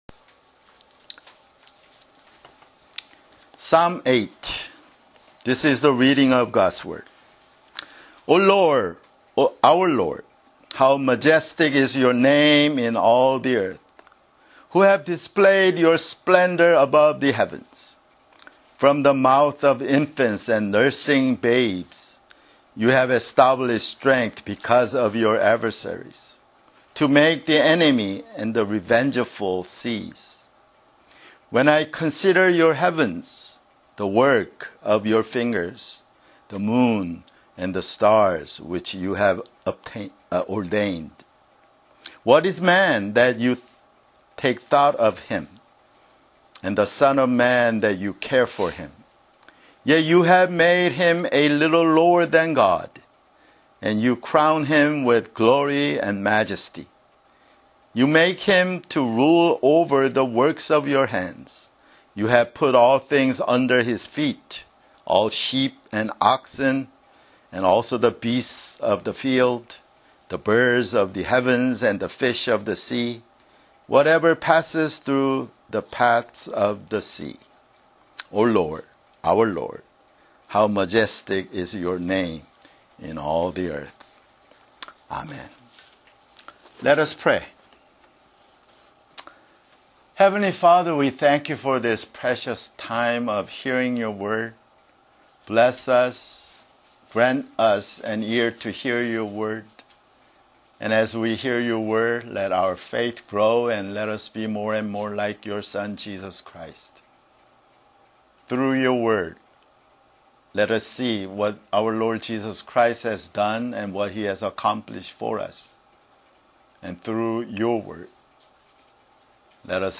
[Sermon] Psalms (9)